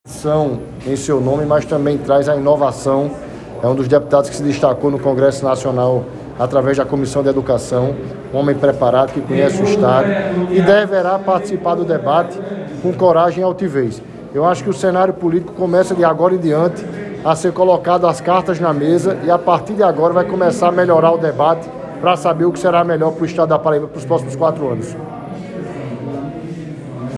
Abaixo a fala do vereador Fernando Milanez, que foi até bem pouco tempo, líder do então prefeito da Capital, Luciano Cartaxo, na Câmara de Vereadores.